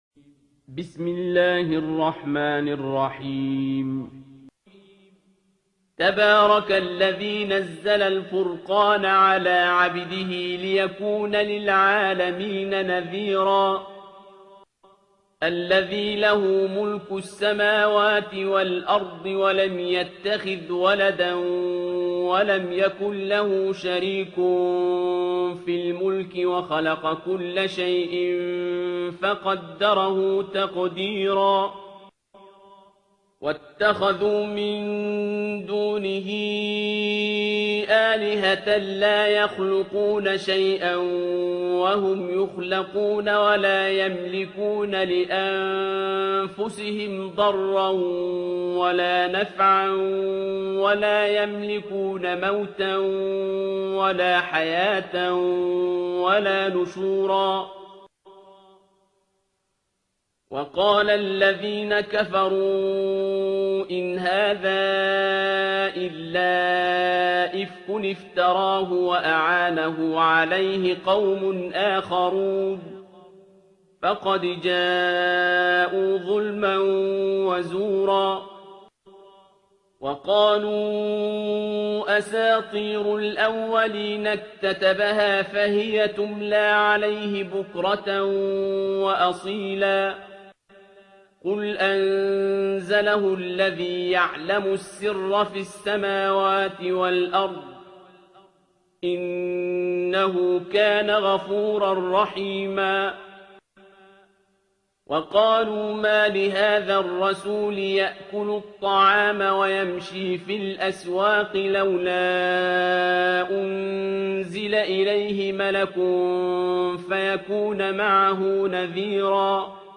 ترتیل سوره فرقان با صدای عبدالباسط عبدالصمد
025-Abdul-Basit-Surah-Al-Furqan.mp3